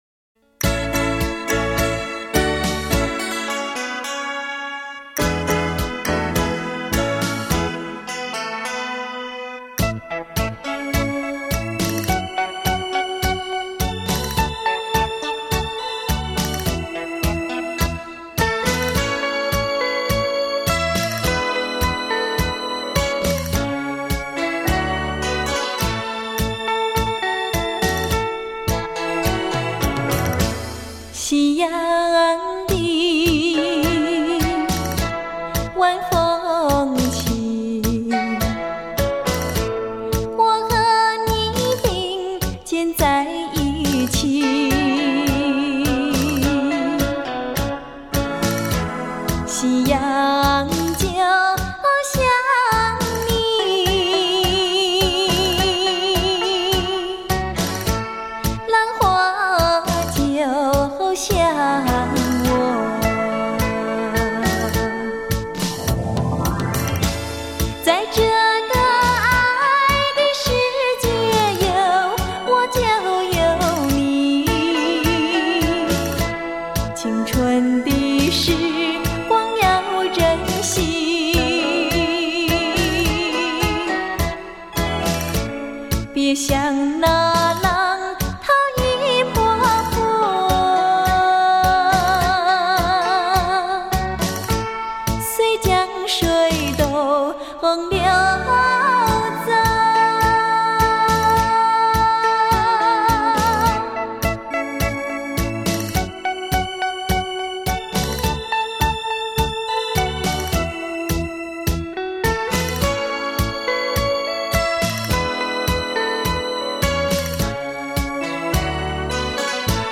典雅温婉的歌声